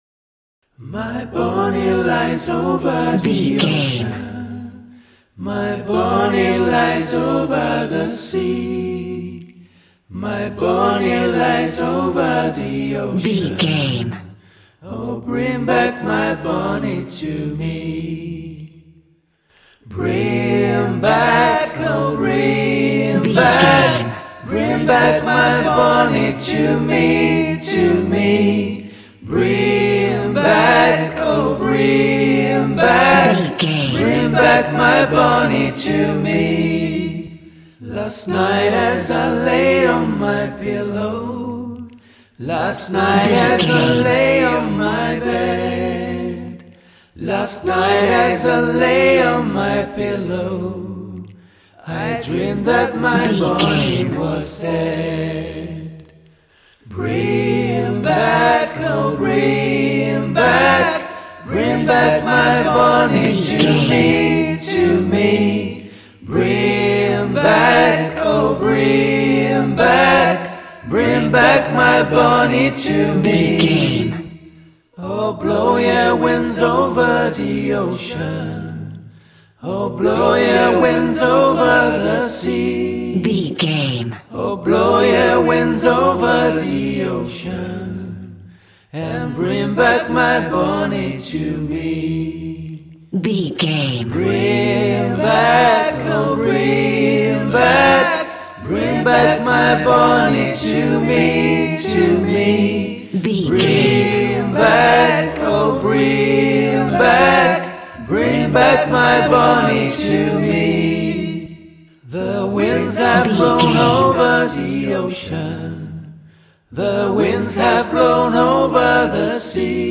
Nursery Rhyme Acapella
royalty free music
Uplifting
Ionian/Major
childlike
happy